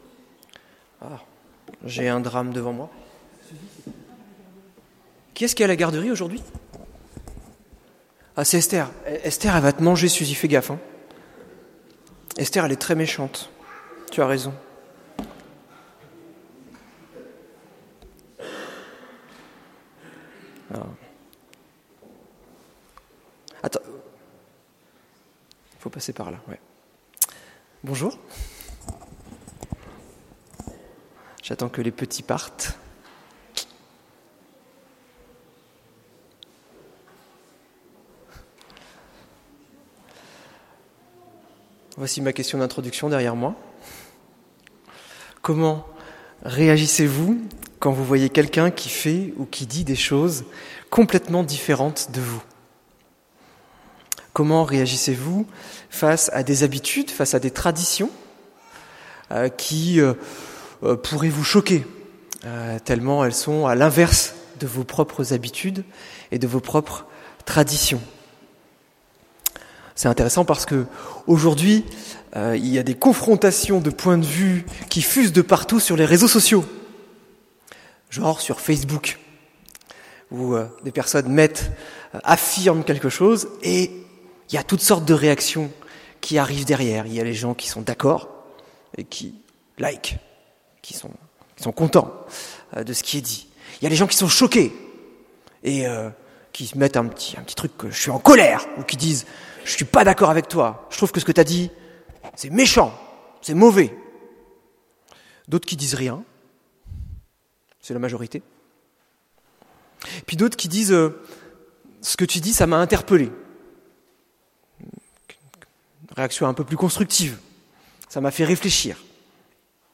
Prédications